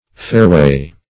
Fairway \Fair"way`\, n.